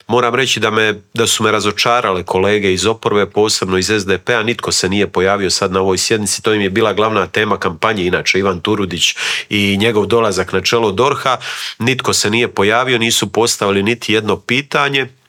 U Intervjuu Media servisa gostovao je upravo Nikola Grmoja i podijelio s nama dojmove sa sjednice.